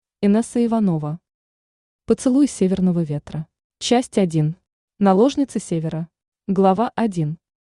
Аудиокнига Поцелуй северного ветра | Библиотека аудиокниг
Aудиокнига Поцелуй северного ветра Автор Инесса Иванова Читает аудиокнигу Авточтец ЛитРес.